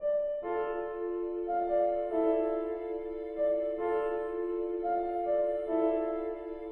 Macros_Piano.wav